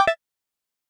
Techmino/media/effect/chiptune/check.ogg at 6b426790c78a0e6830fe0cbcf032da10d8005ce4